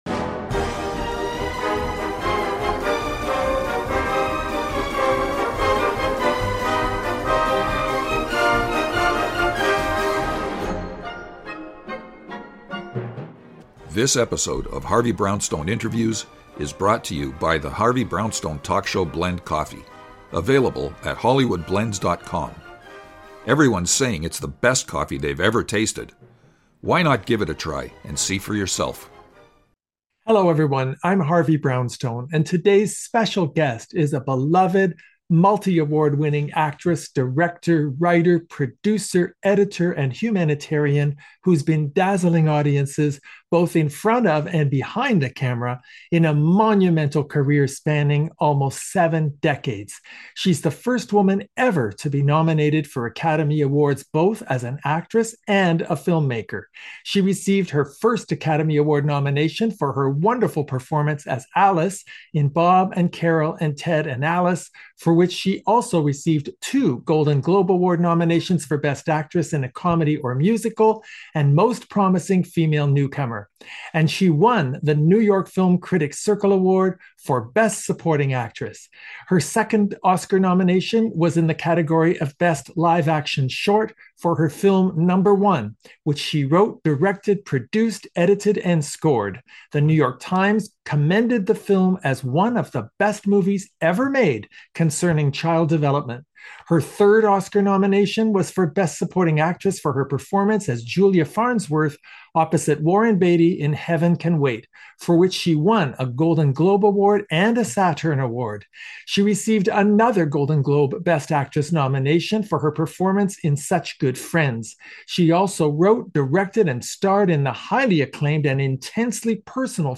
In-depth Interviews, of celebrities, authors and notable people who have made a significant difference in the world, by retired judge Harvey Brownstone